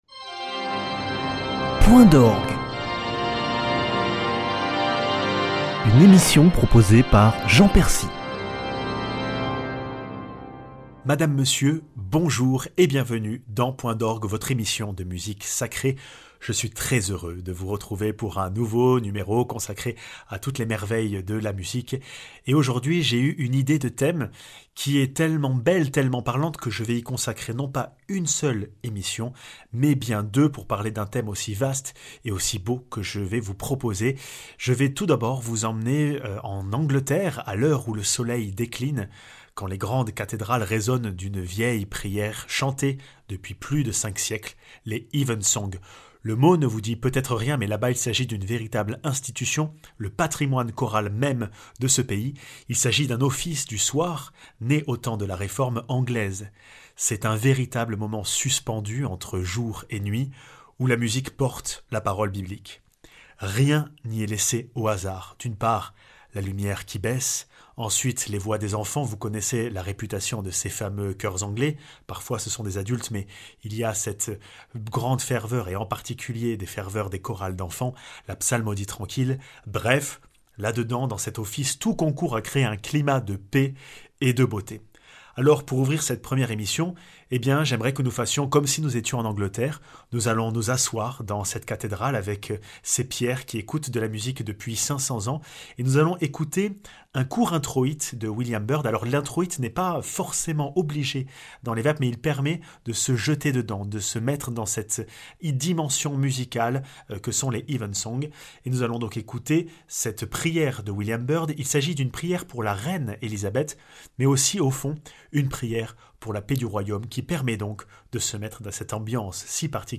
Patrimoine choral mondialement célèbre, Point d'orgue vous plonge dans la liturgie anglicane (O Lord, make thy servant de Byrd, Psalm 23 de Goss, Magnificat en sol de Stanford et Nunc Dimittis de Howells)